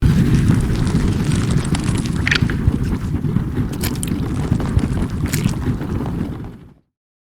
Mudslide — Free Sound Effect Download
Mudslide
yt_CfJrSNRxojk_mudslide.mp3